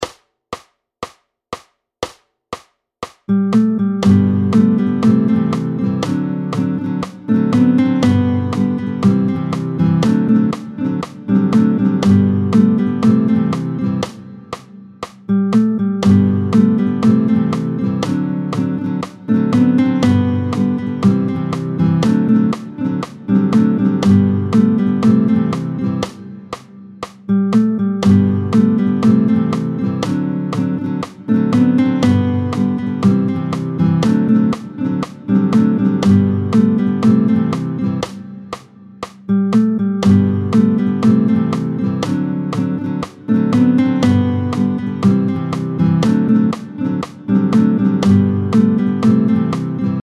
Ensemble
Rapide, tempo 120